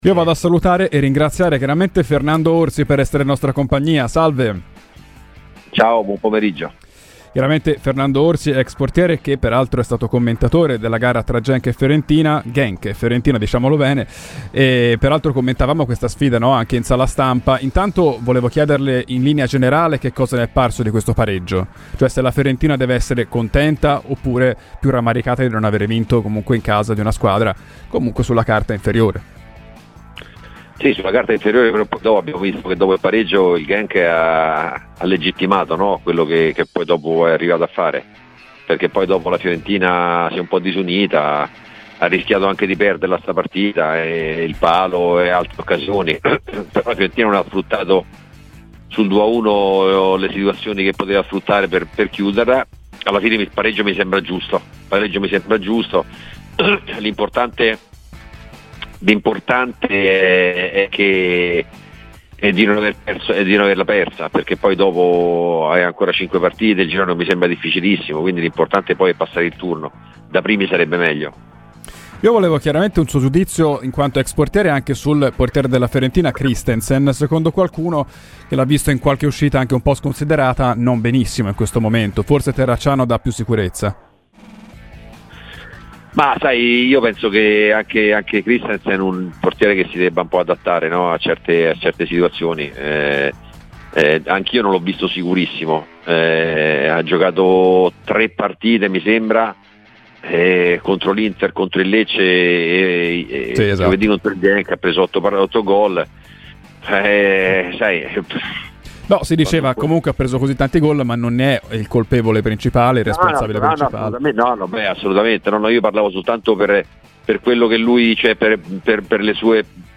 L'ex portiere ed allenatore, ora apprezzata seconda voce Sky, Fernando Orsi è intervenuto a Radio Firenzeviola durante "Viola Weekend" per parlare di Fiorentina, commnentata in telecronaca a Genk, da cui appunto parte: "Il Genk dopo il pareggio ha legittimato quello che è riuscito a fare, con il palo ed altre azioni. La Fiorentina però non ha sfruttato le occasioni per chiudere la partita sul 2-1".